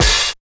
69 OP HAT.wav